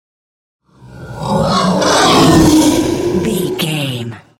Whoosh large creature
Sound Effects
Atonal
ominous
dark
eerie
roar